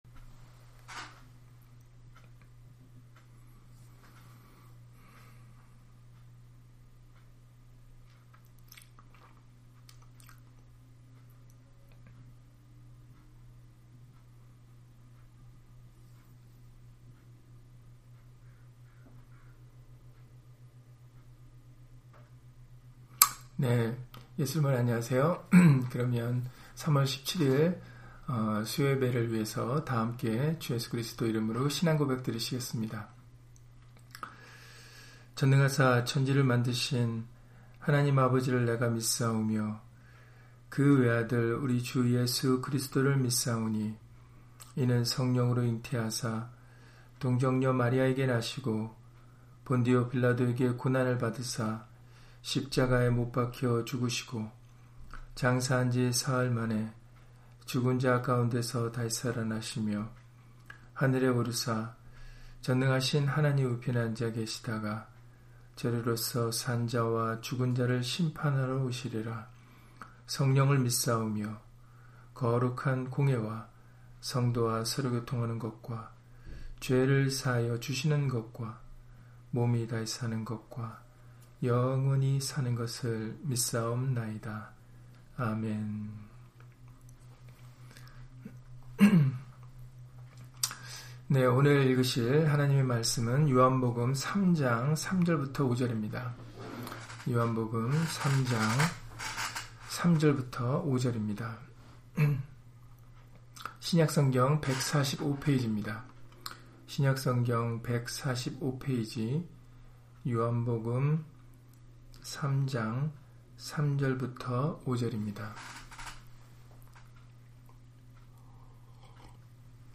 요한복음 3장 3-5절 [사람이 거듭나지 아니하면] - 주일/수요예배 설교 - 주 예수 그리스도 이름 예배당